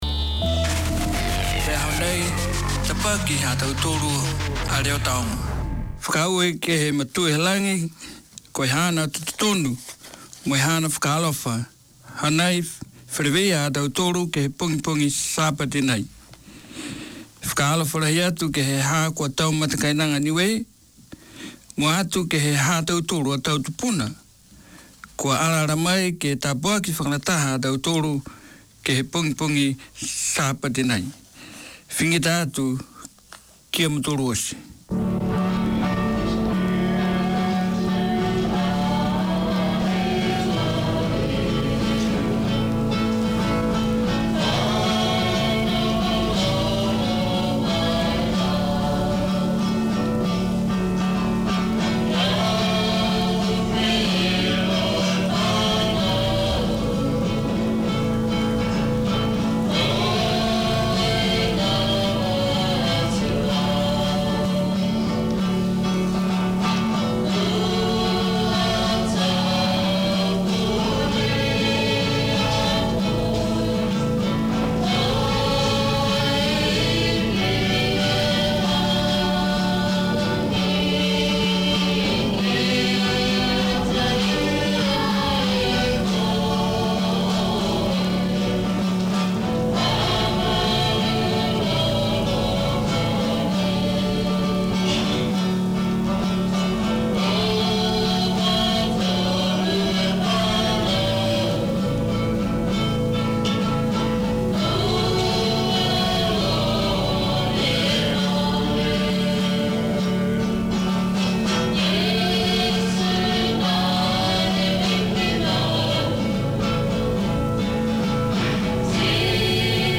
This is co-operative airtime shared between three Niuean Christian churches from around the Auckland region. The churches come to your place with a weekly rotation of services including preaching, singing, playing and praying. There are gospel songs and gospel lessons, praise and testifying.